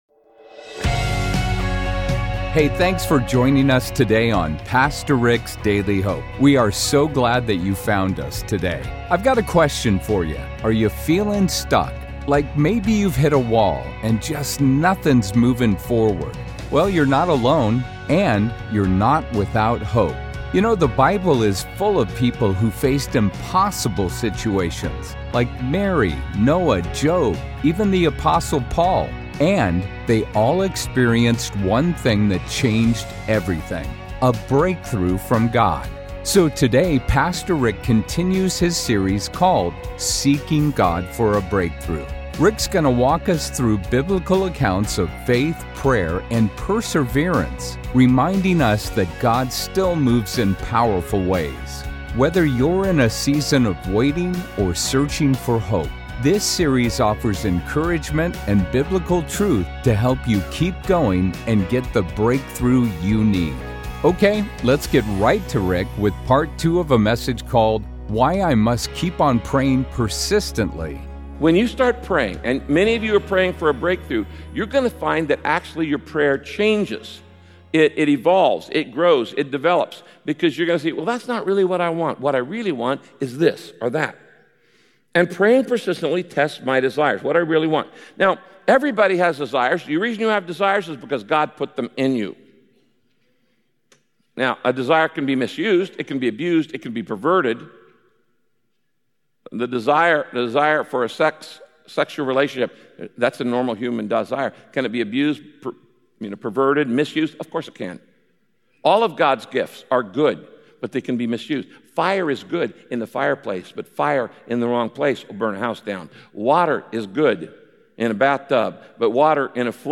While we work on having a more vibrant prayer life, God is working on us. Discover what God wants to teach you while you wait on him in prayer in today’s message with Pastor Rick.